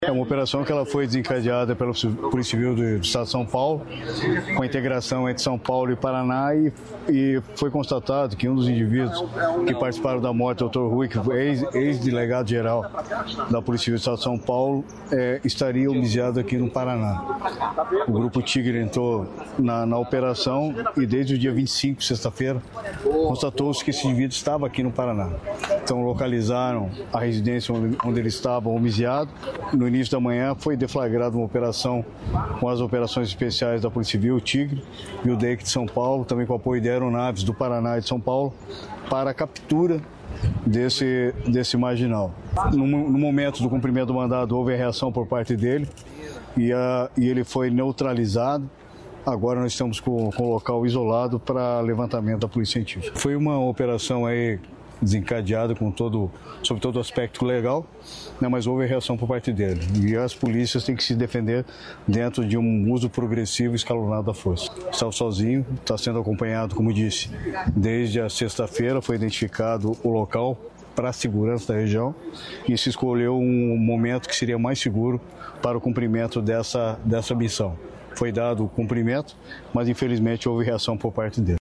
Sonora do secretário da Segurança Pública, Hudson Leôncio Teixeira, sobre Operação da PCPR e PCSP